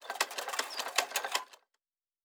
Metal Tools 10.wav